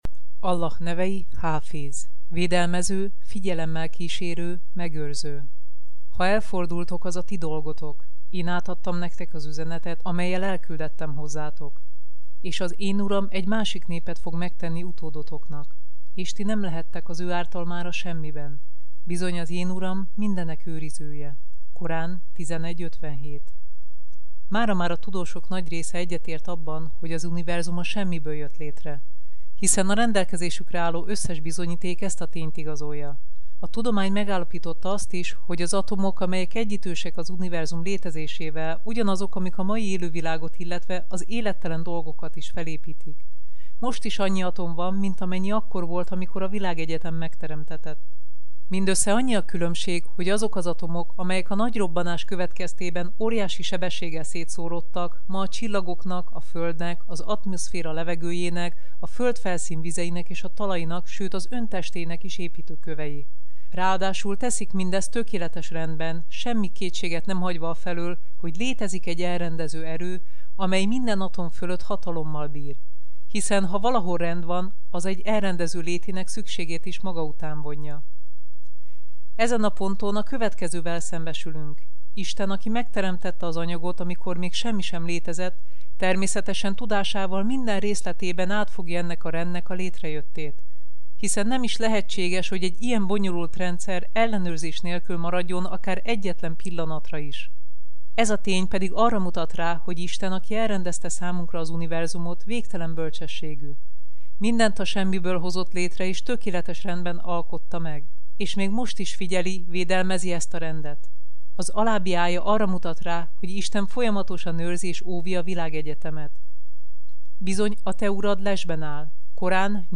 Allah nevei hangoskönyv